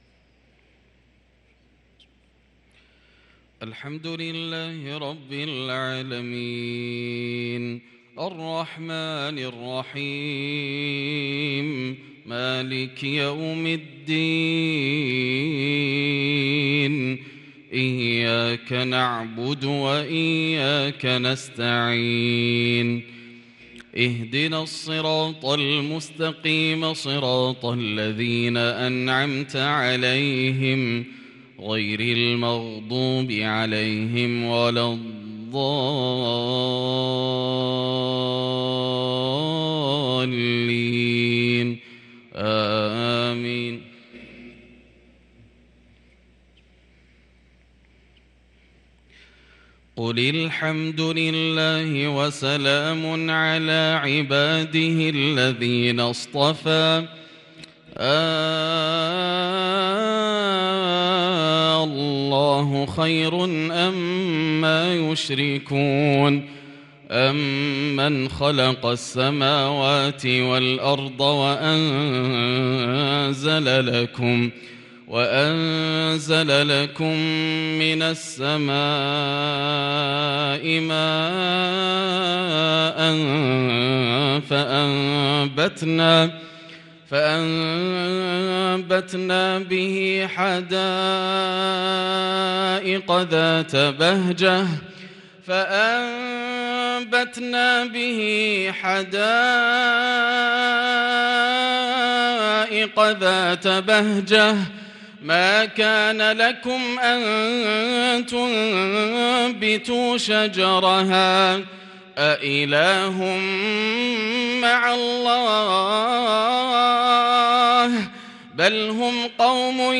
صلاة العشاء للقارئ ياسر الدوسري 4 ربيع الآخر 1444 هـ
تِلَاوَات الْحَرَمَيْن .